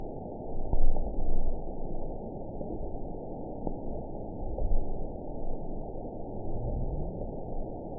event 920695 date 04/03/24 time 18:22:21 GMT (1 year, 2 months ago) score 8.32 location TSS-AB01 detected by nrw target species NRW annotations +NRW Spectrogram: Frequency (kHz) vs. Time (s) audio not available .wav